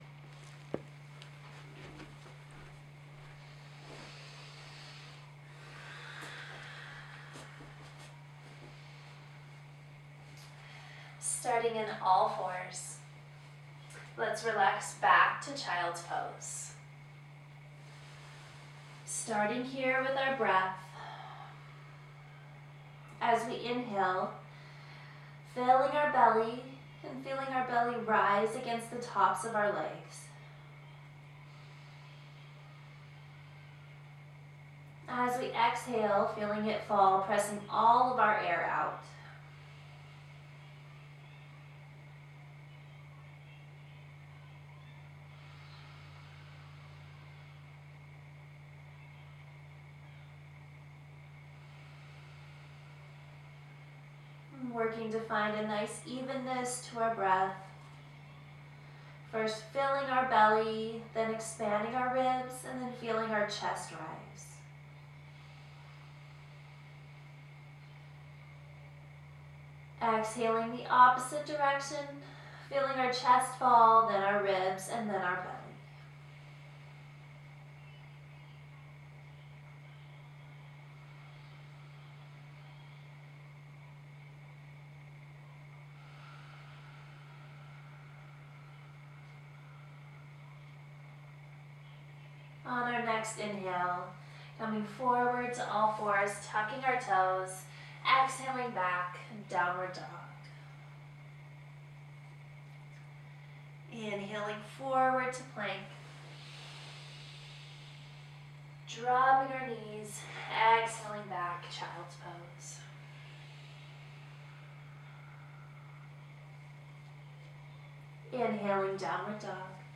Once you are confident, you may prefer to follow along with the flow sequence in audio only to allow a deeper connection to your body, breath and spirit.